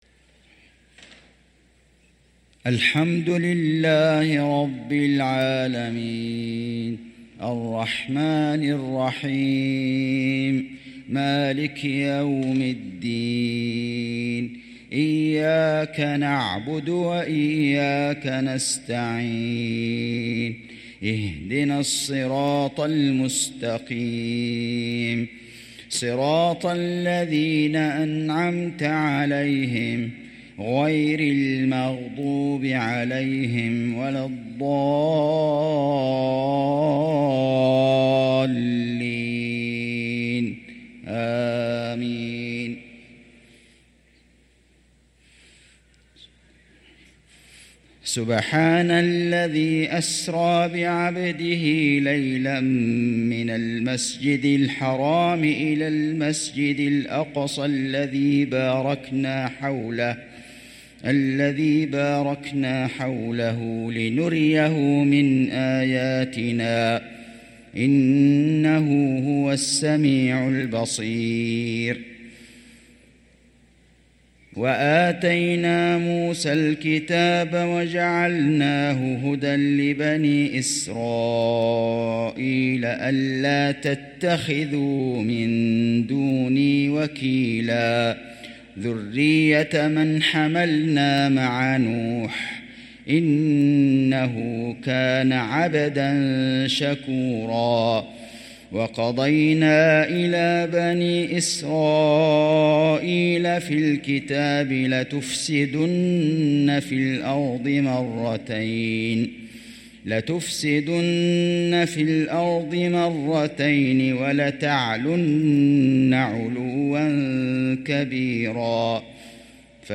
صلاة العشاء للقارئ فيصل غزاوي 8 جمادي الأول 1445 هـ
تِلَاوَات الْحَرَمَيْن .